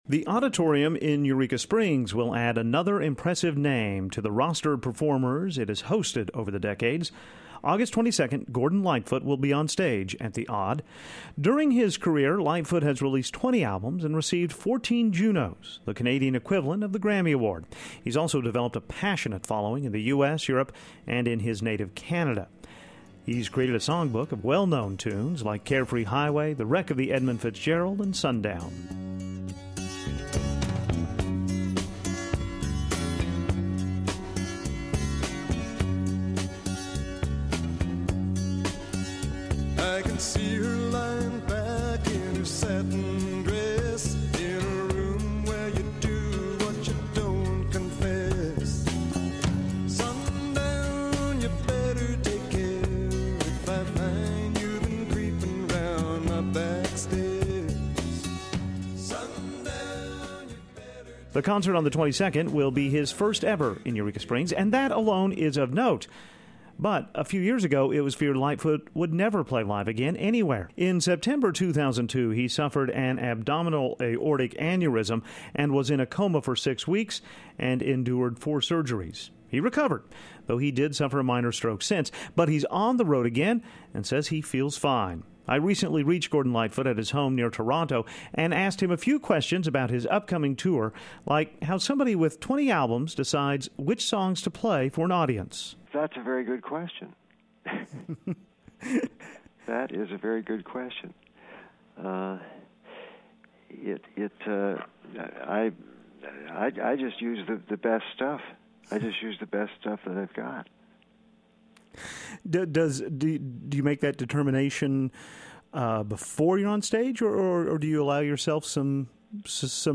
Gordon Lightfoot brings his collection of songs like “Sundown” and “The Wreck of the Edmund Fitzgerald” to Eureka Springs this month. He spoke with Ozarks at Large from his home.